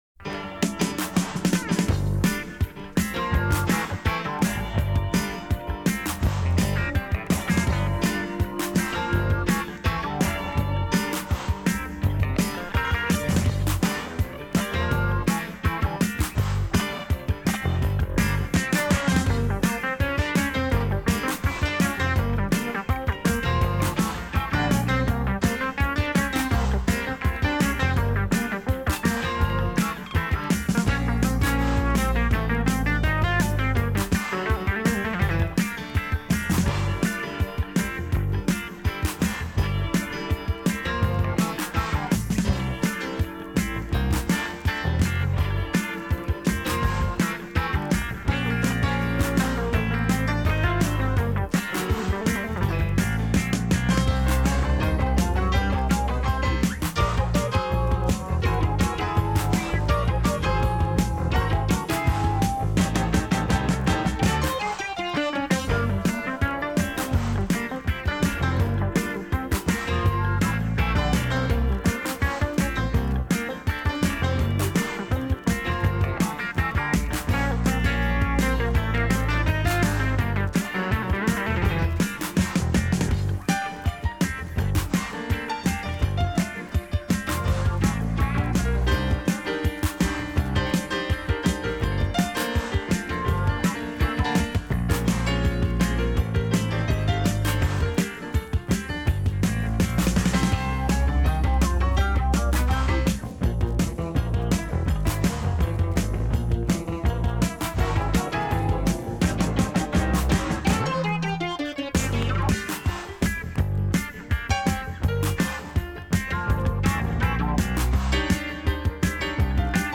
Жанр: арт-рок, Хард-рок
Style:Pop Rock